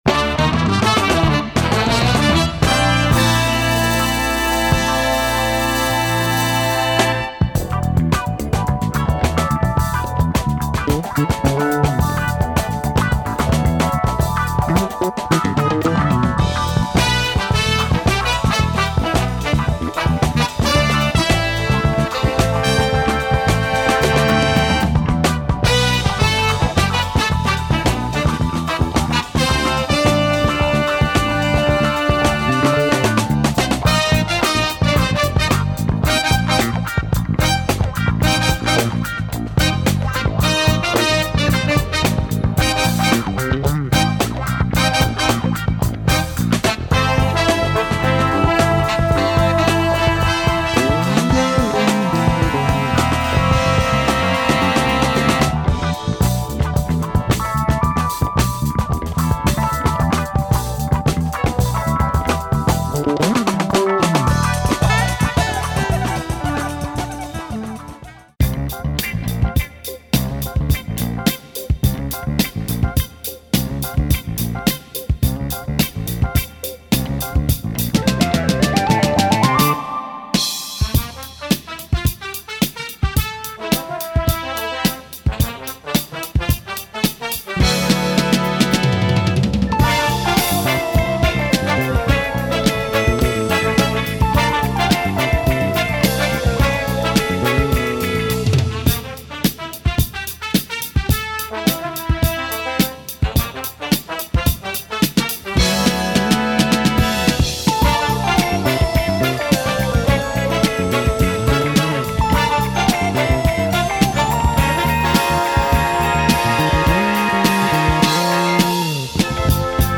Brazilian funk